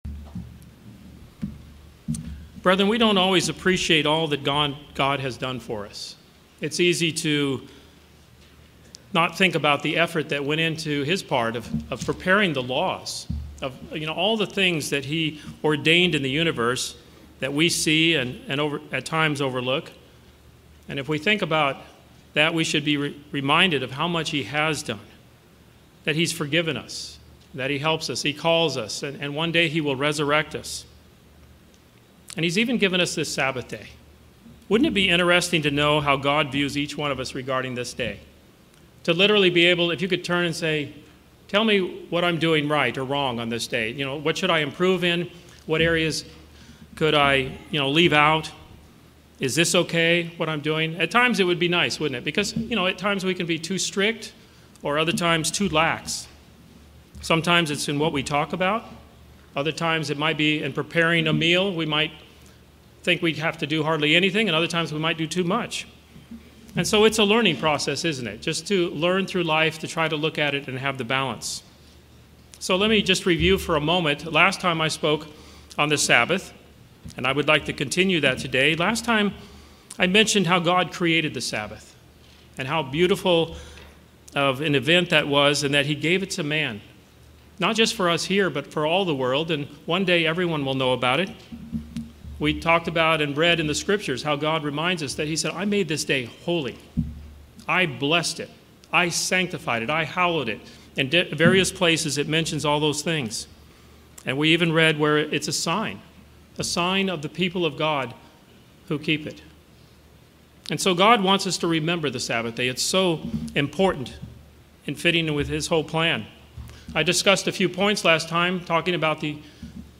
The weekly Sabbath plays a significant role in the lives of Christians. This sermon covers three additional points which are helpful in fulfilling the command to delight in the Sabbath.
Given in Orlando, FL